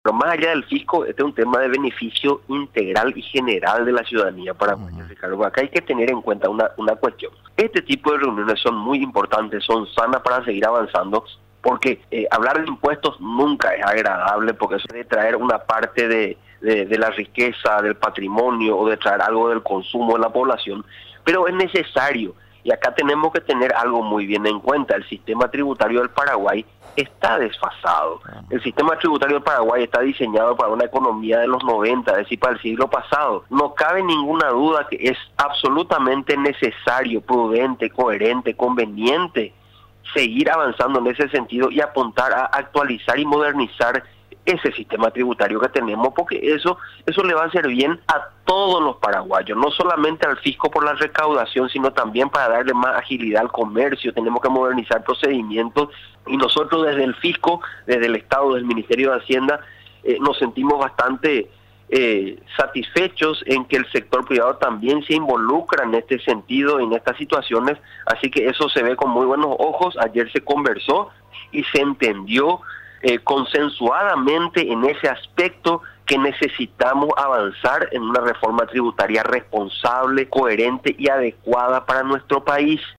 El Ministerio de Hacienda y empresarios acordaron impulsar una modificación tributaria integral, con la finalidad de consensuar los impuestos a ser transformados, explicó el viceministro de Tributación Fabián Domínguez.